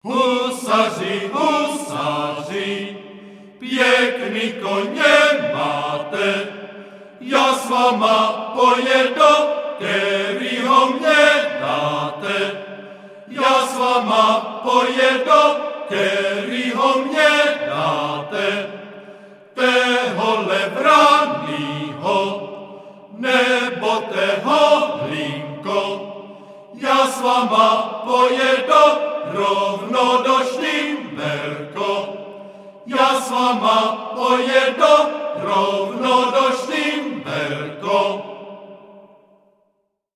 Žánr: World music/Ethno/Folk
písní a capella